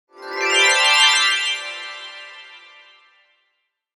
Celestial-chimes-sound-effect.mp3